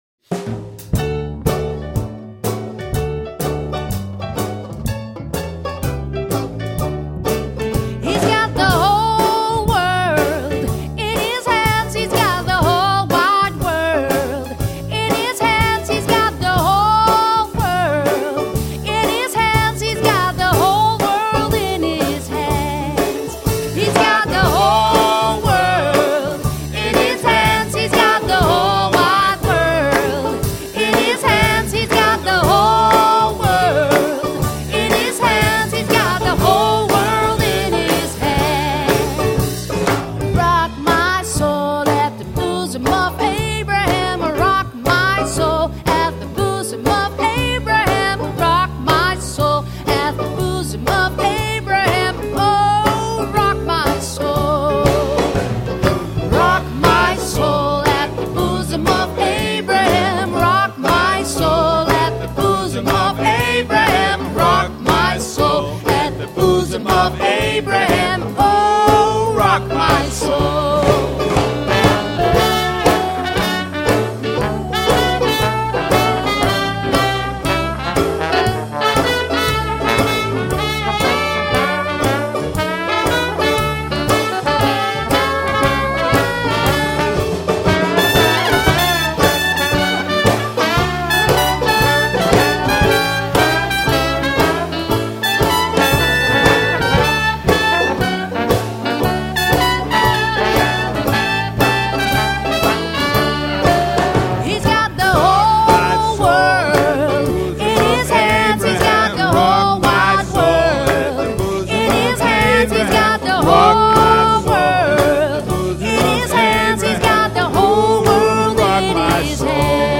Gospels